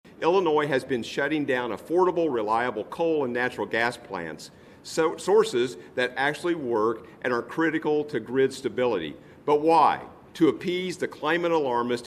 Republican State Representative Brad Halbrook said while Governor J.B. Pritzker blames the increased costs on grid operators and President Donald Trump, Democrats need to reverse course.